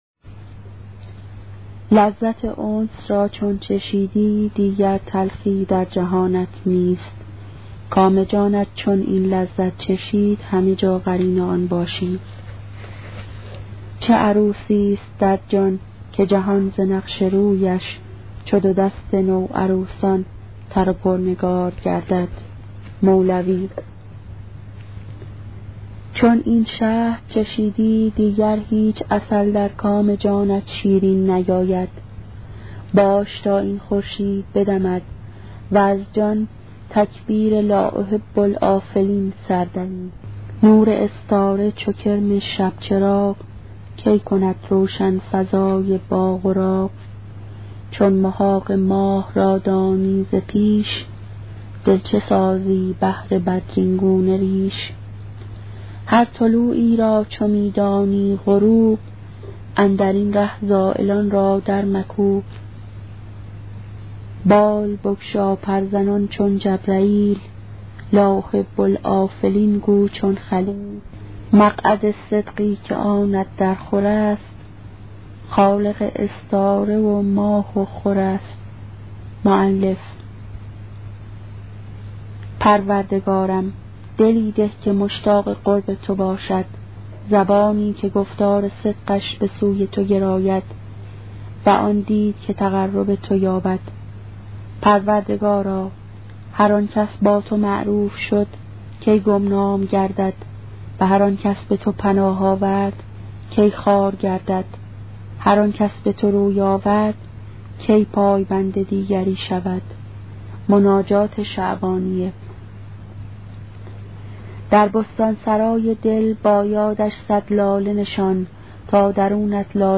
کتاب صوتی عبادت عاشقانه , قسمت سوم